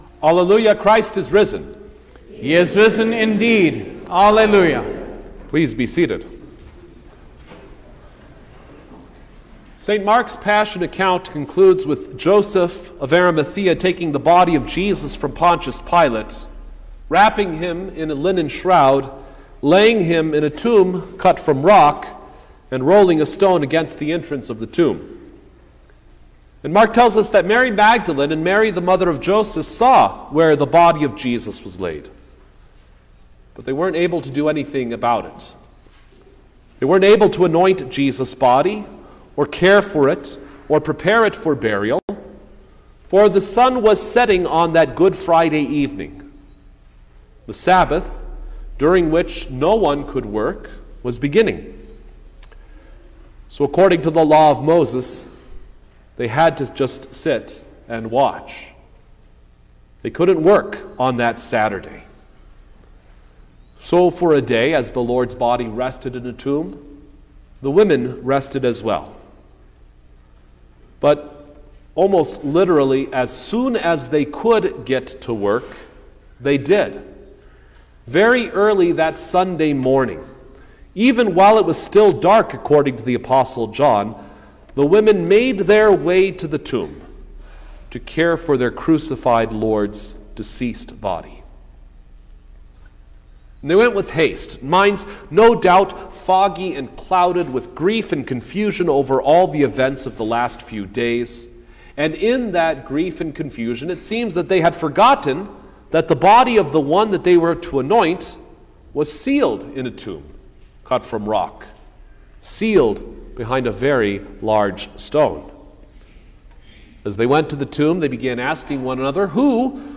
April-9_2023_Easter-Sunday-Service_Sermon-Stereo.mp3